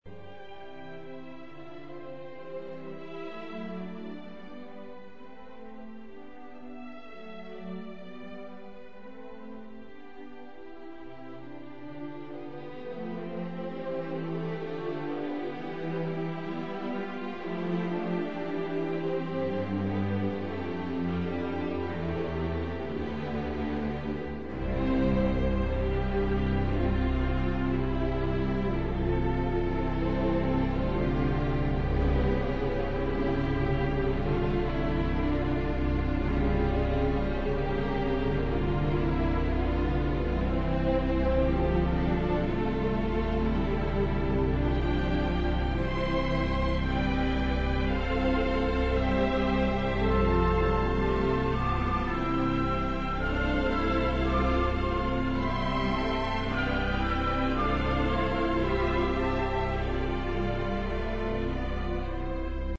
یک فوگ آرام و صحیح که طبق گفته آیوز “رجعت زندگی به صورت پرستی و آیین باوری” را بیان می کند.